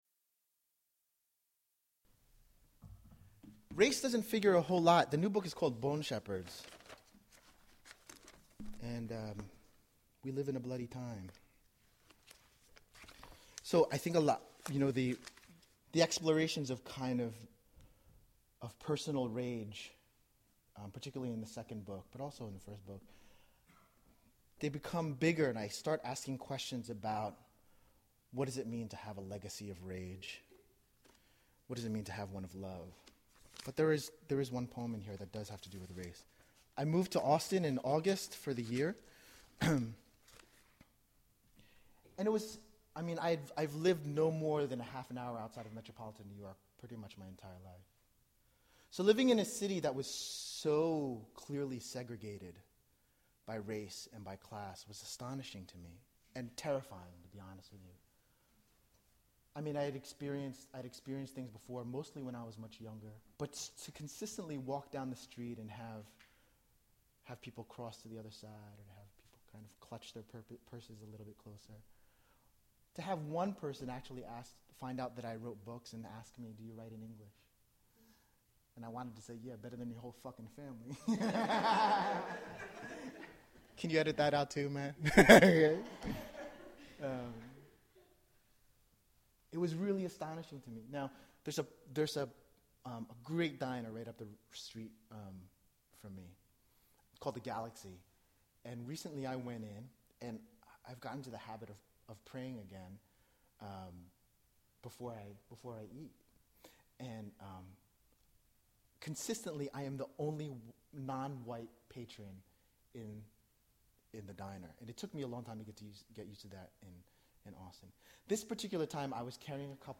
Wolf Prays at the Galaxy (live, Bates)